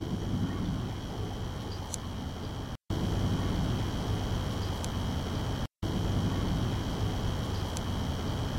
Rose Hill 5 EVP #7 -  Right after we mention the coconut shell on the tombstone from the last EVP clip (#6)  a female voice responds very faintly.  It sounds like she is saying "He left me.".